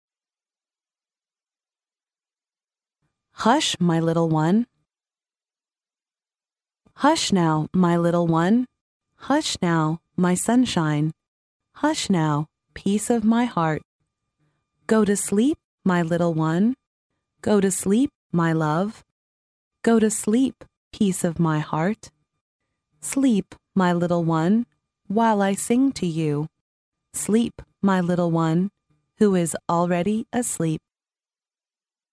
幼儿英语童谣朗读 第4期:安静我的小宝贝 听力文件下载—在线英语听力室